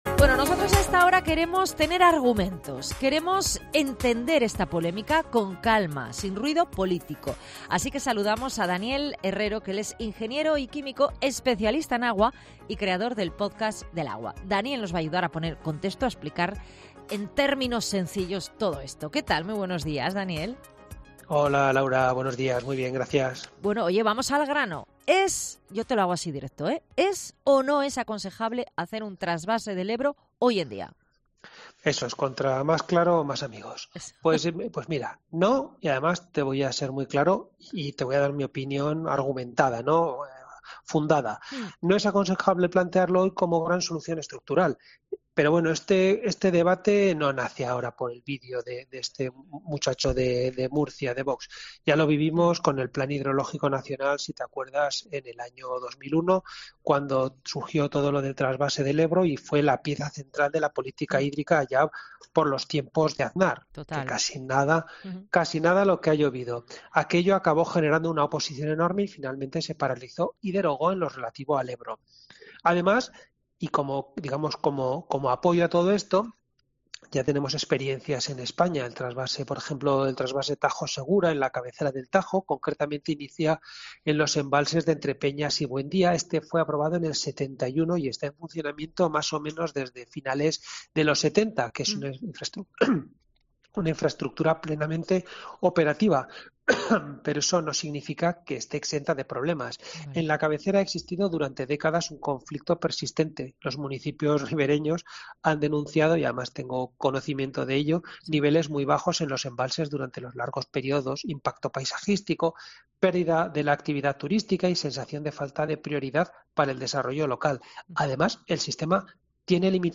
Un ingeniero experto en agua analiza en COPE la polémica y advierte de los riesgos de una infraestructura que podría generar nuevos y viejos problemas